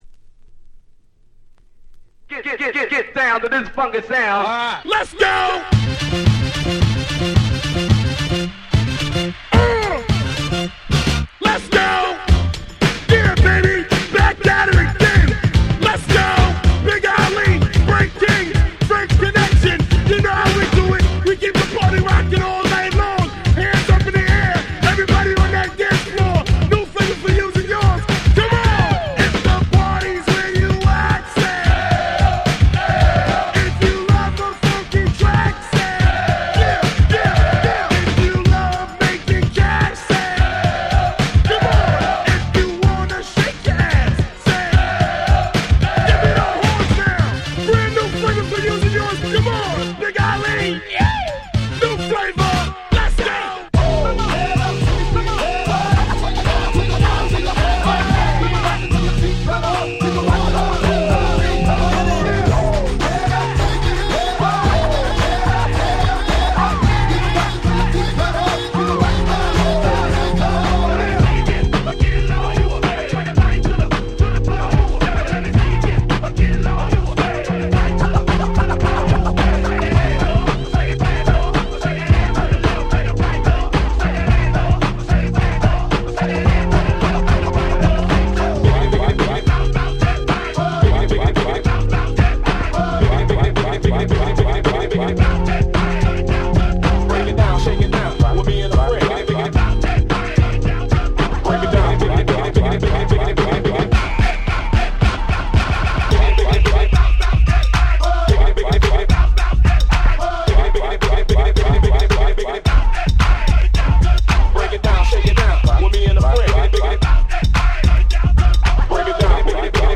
White盤オンリーのアゲアゲParty Tracks/勝手にRemix物！！